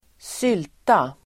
Uttal: [²s'yl:ta]